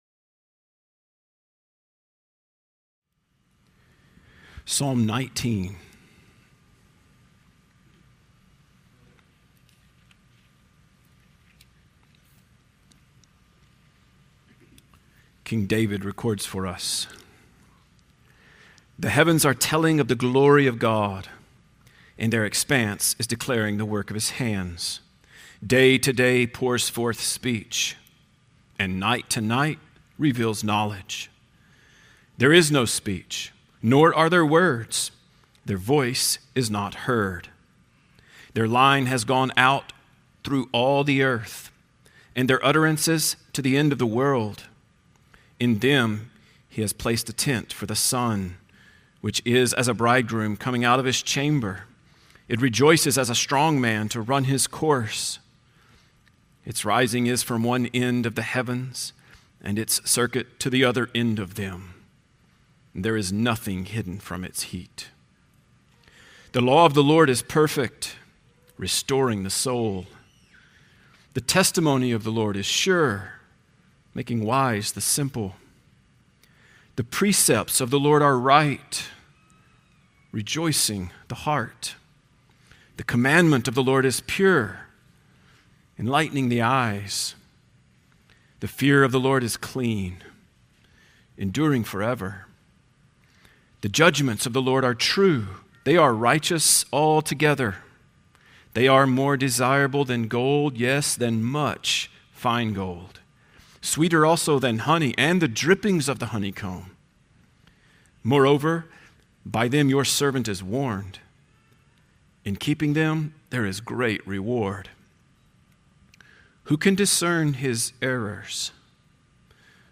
A Grateful Life | SermonAudio Broadcaster is Live View the Live Stream Share this sermon Disabled by adblocker Copy URL Copied!